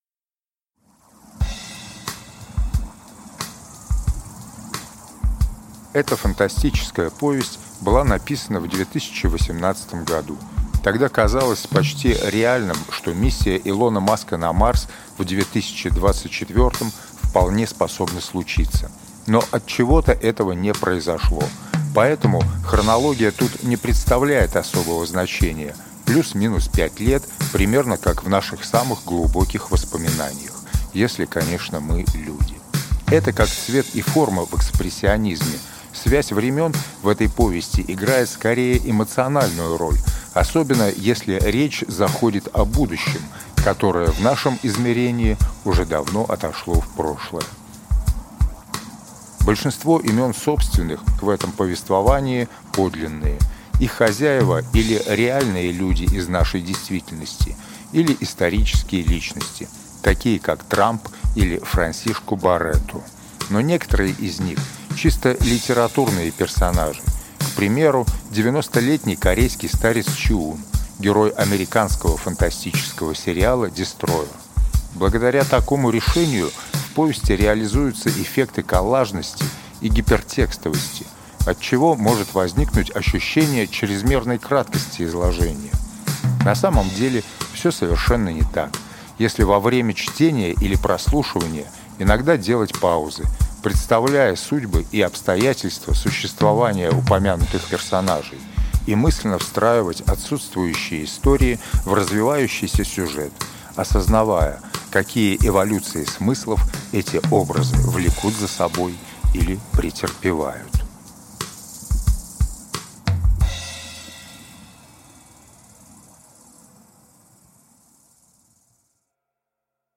Аудиокнига Аяхуаска | Библиотека аудиокниг